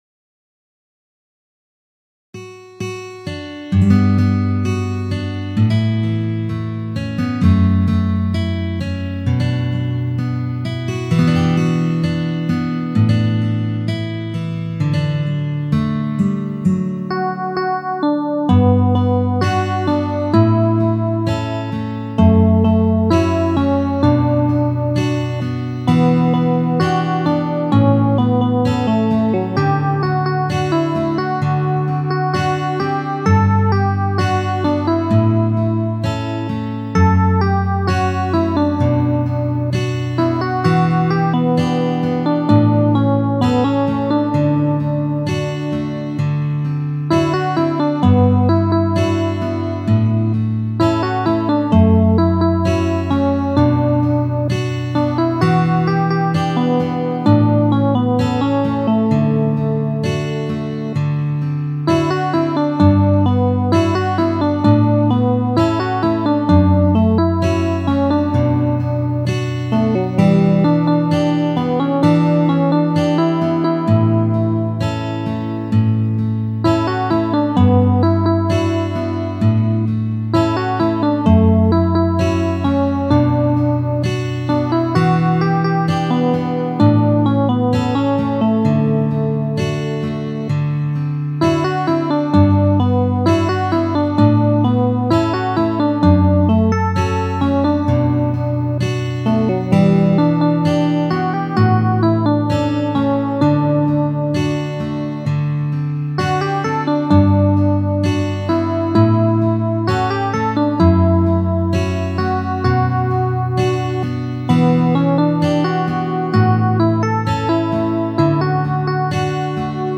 因为这些歌都不是同一个调，所以改编成一个调后，需要根据自己的嗓子音高来夹变调夹，试听做的夹2品。